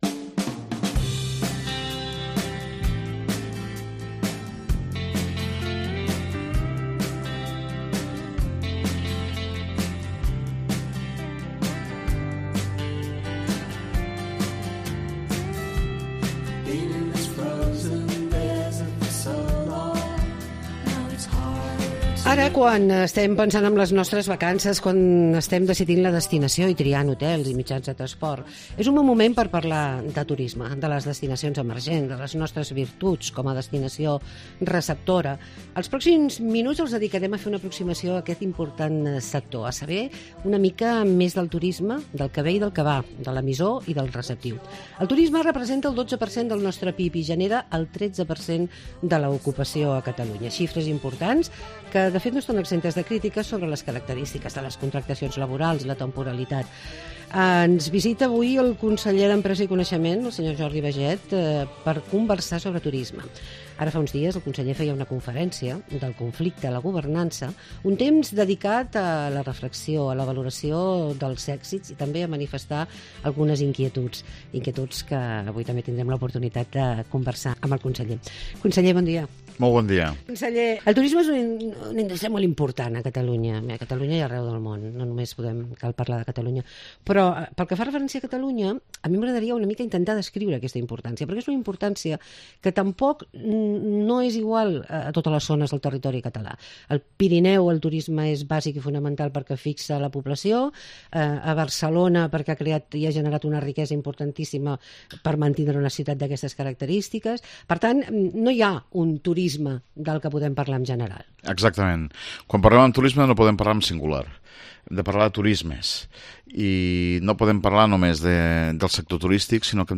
Entrevista amb el conseller d'Empresa i Ocupació, Jordi Baiget